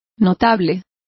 Complete with pronunciation of the translation of notables.